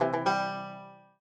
banjo_ceeg.ogg